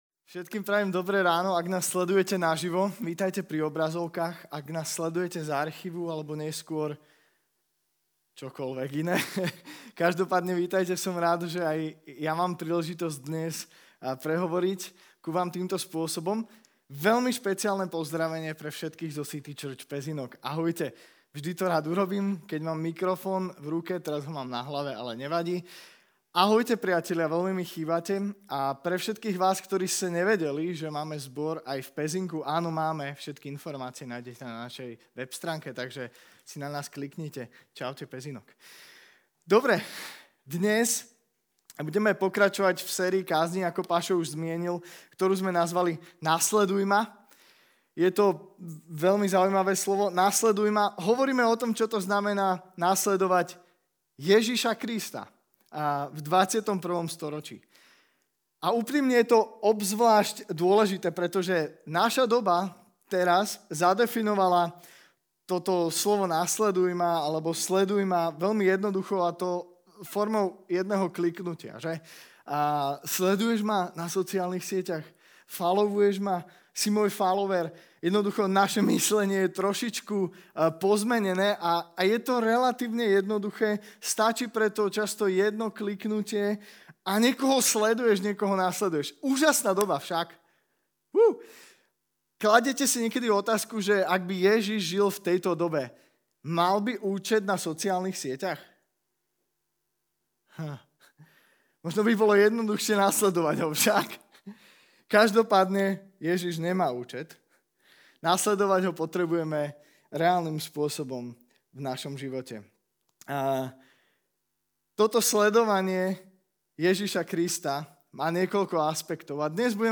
Kázeň týždňa Zo série kázní